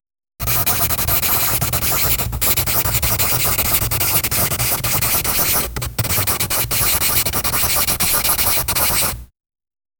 Realistic pencil drawing sound, clean and close. A sharp graphite pencil drawing straight lines on paper. Four separate strokes, each one short and precise, forming the four sides of a square. Small pauses between strokes. Dry, mid-frequency sound, no bass, no room reverb. Intimate, tactile, neutral, technical. Sounds like drafting or technical drawing, not art. No music, no atmosphere, no cinematic elements.
realistic-pencil-drawing--te4re2ek.wav